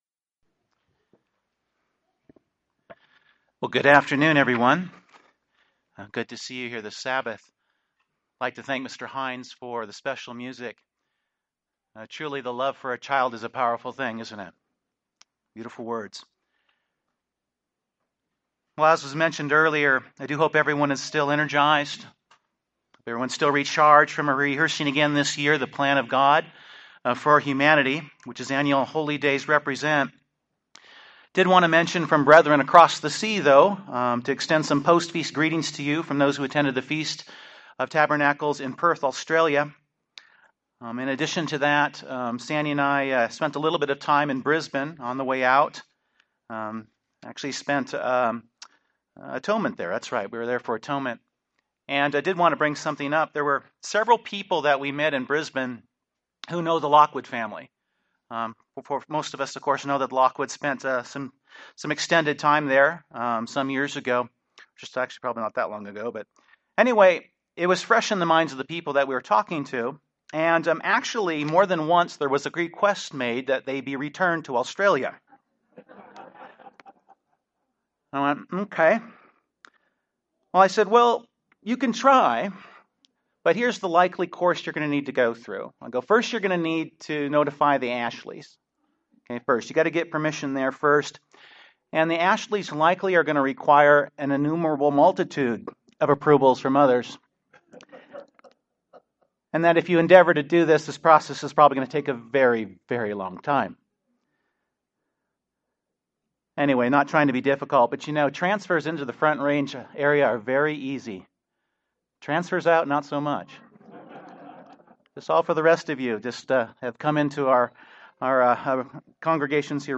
Scripture has many references of what it means to be rich. This sermon focuses on the questions – are we rich and are we rich toward God? (Luke 12:21).